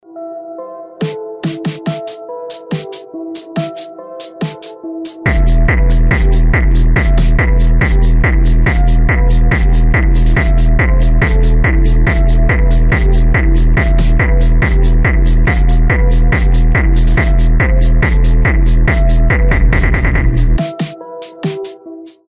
• Качество: 320, Stereo
Electronic
мощные басы
EBM
Техно